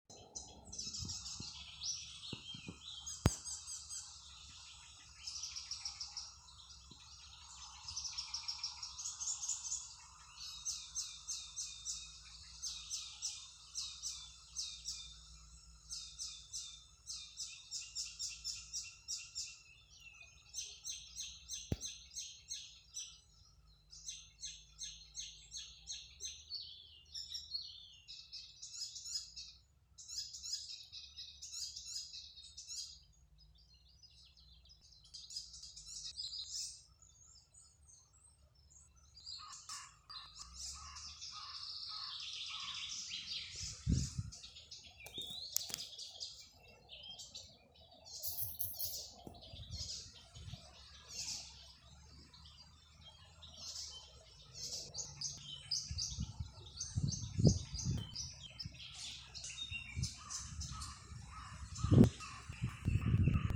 Icterine Warbler, Hippolais icterina
Administratīvā teritorijaVecumnieku novads
StatusSinging male in breeding season